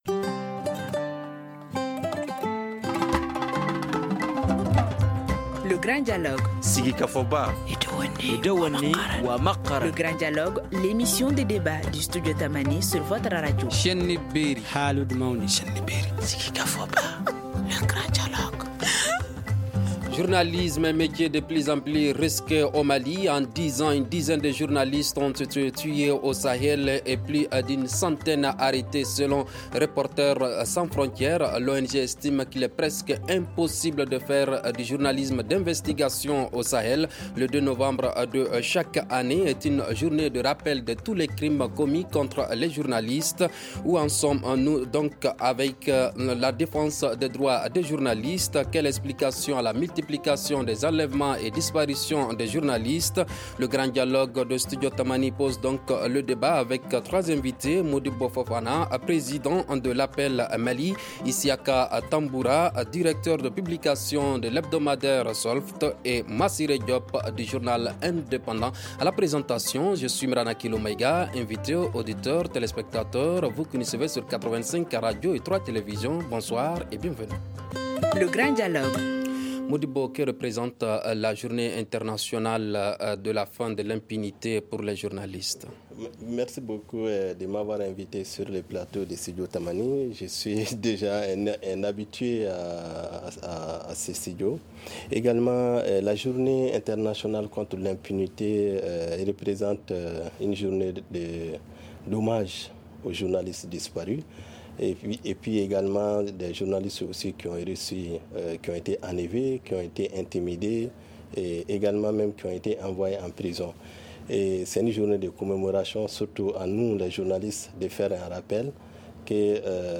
Le Grand dialogue pose le débat avec nos invités :